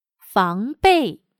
防备/Fángbèi/Evitar, tomar precauciones contra.